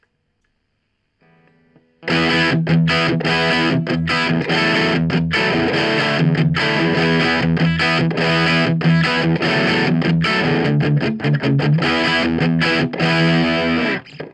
Crunch2.mp3